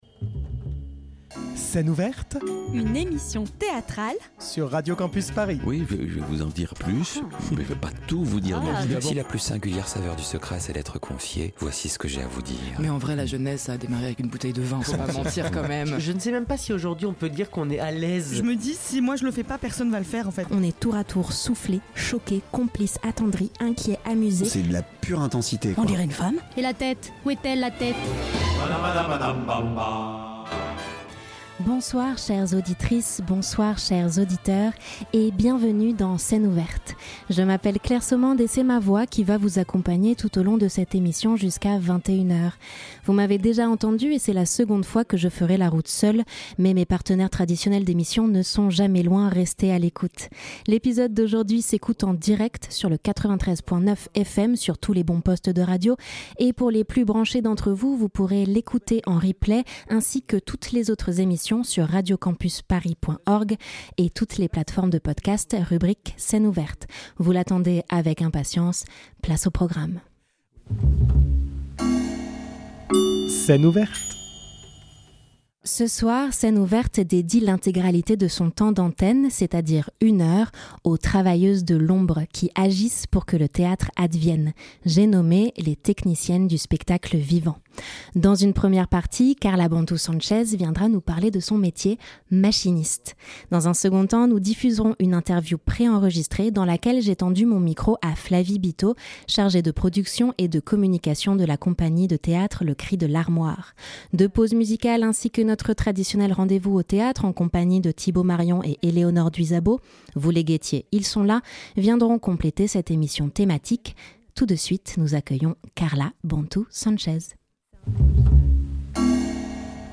Ce soir, Scène Ouverte tend le micro aux artistes de l'ombre qui font vivre le spectacle vivant.